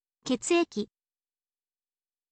ketsu eki